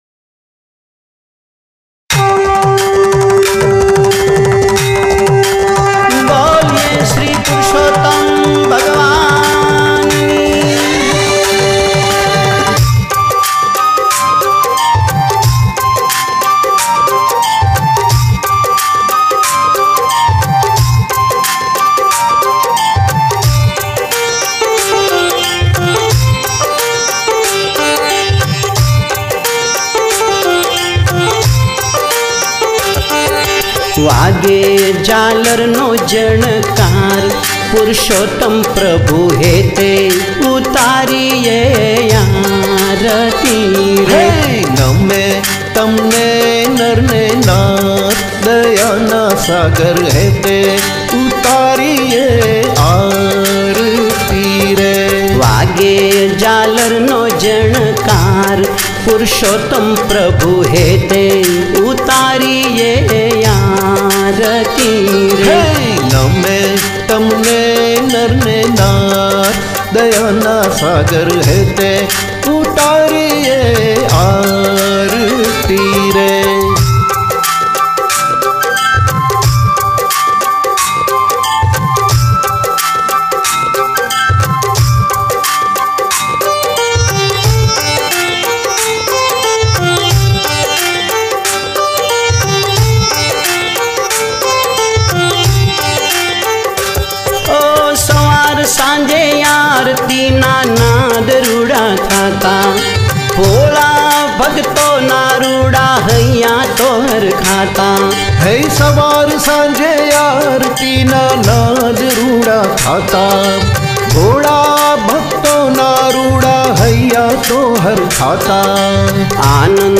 Gujarati Aarti